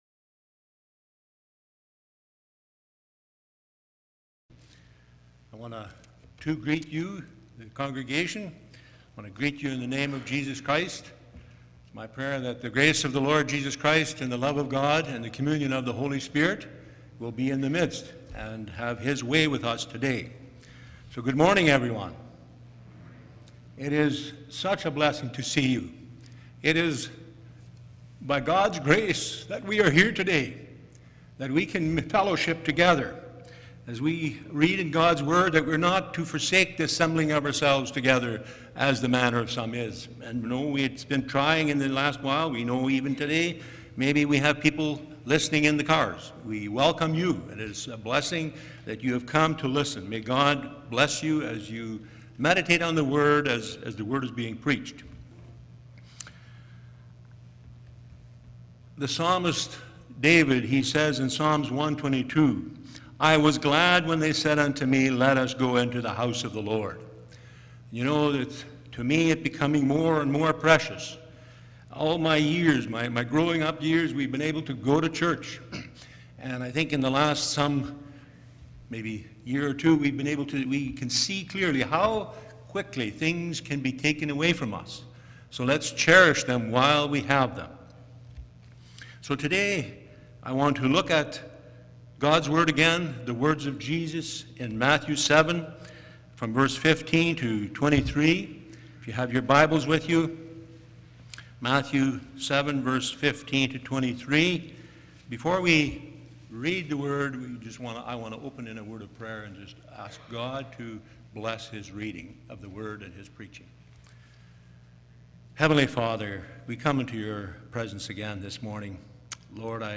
Matthew Passage: Matthew 7:15-23 Service Type: Sunday Morning « Are You the One that Should Come?